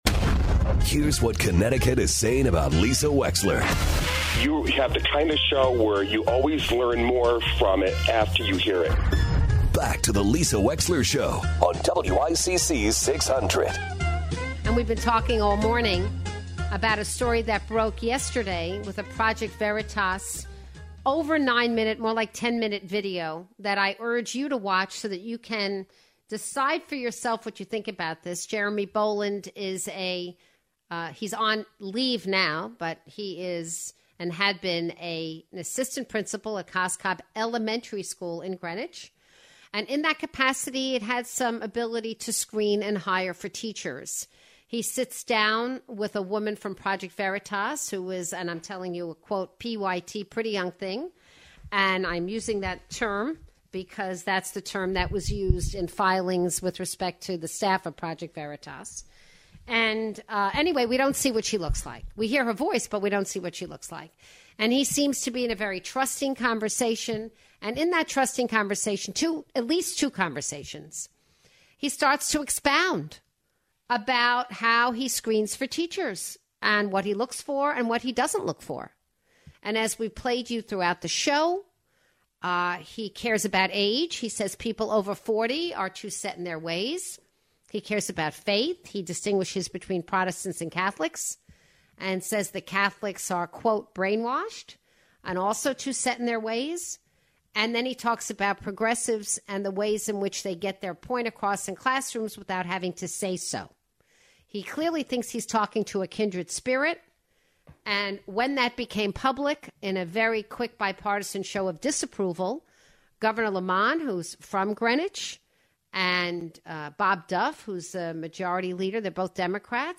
Greenwich First Selectman Fred Camillo calls in to talk about the controversial video released by Project Veritas, concerning hiring practices at Cos Cobb School.